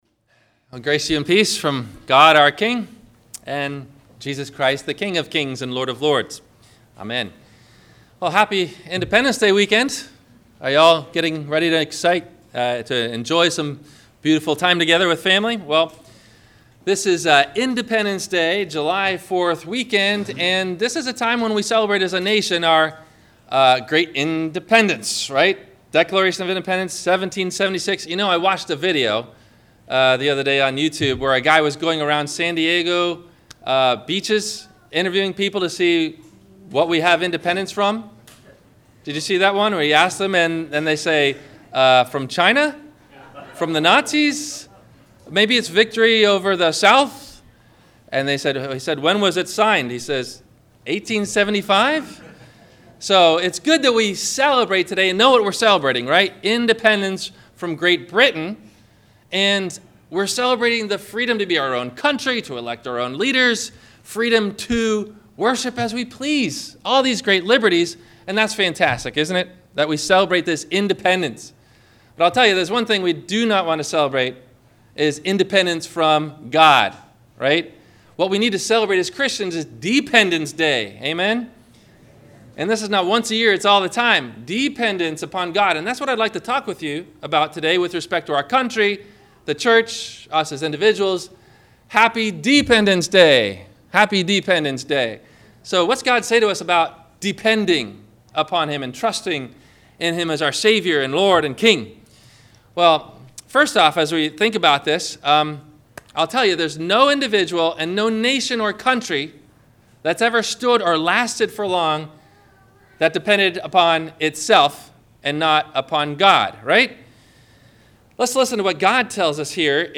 Dependence Day – Sermon – July 03 2011